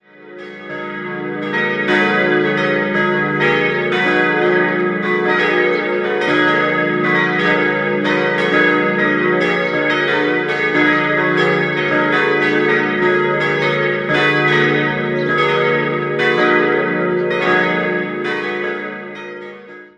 4-stimmiges ausgefülltes Es-Moll-Geläute: es'-ges'-as'-b'